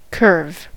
curve: Wikimedia Commons US English Pronunciations
En-us-curve.WAV